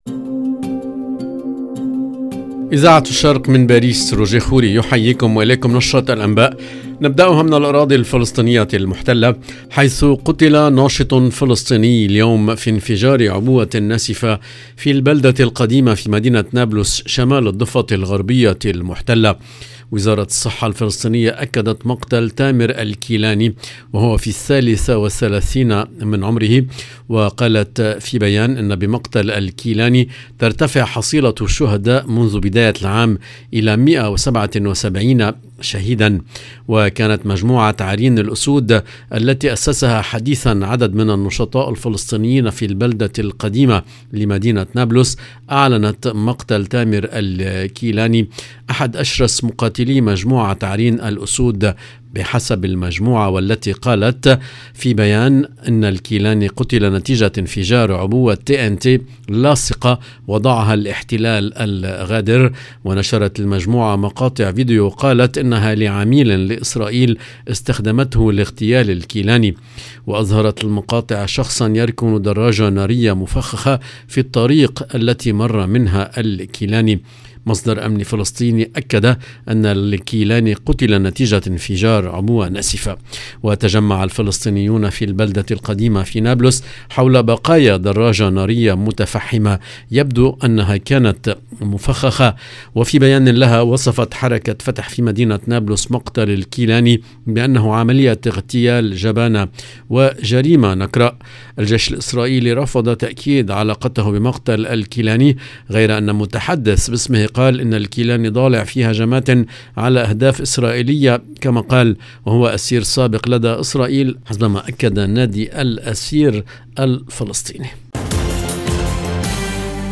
EDITION DU JOURNAL DU SOIR EN LANGUE ARABE DU 23/10/2022